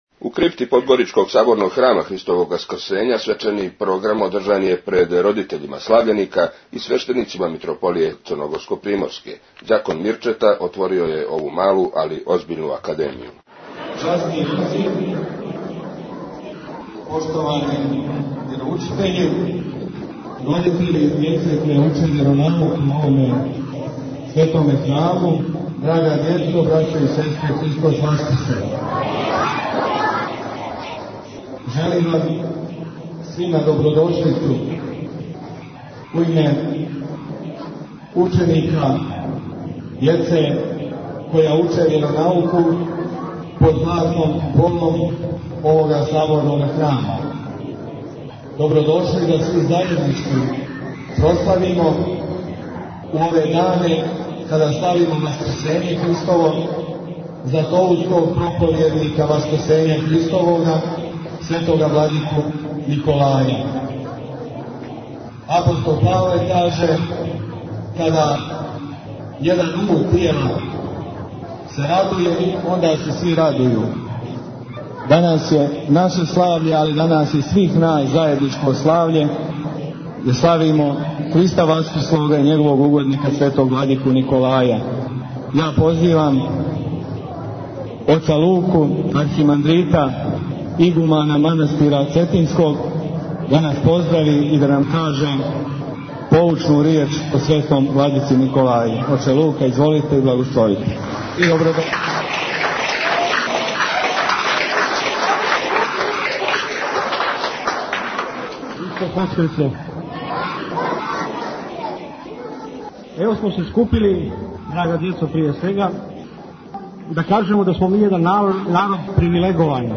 Извјештаји